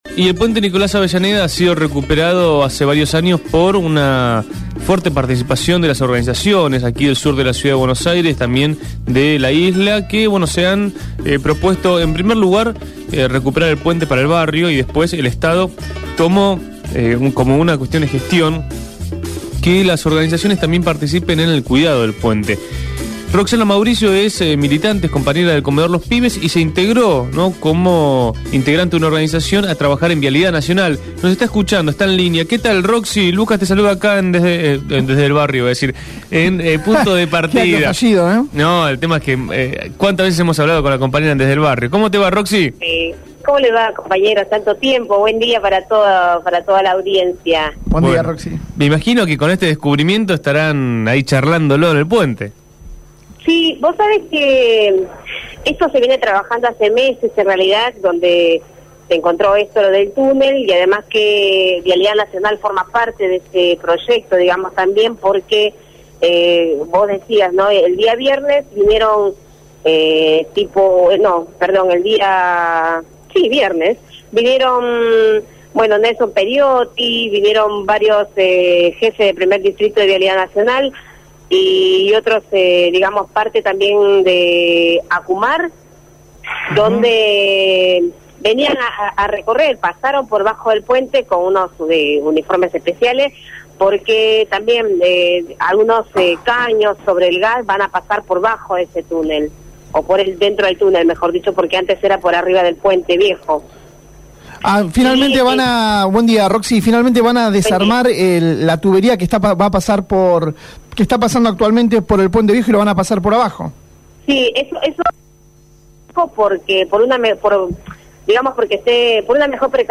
Conectar cada vez más la Isla Maciel con La Boca | Radio Grafica FM 89.3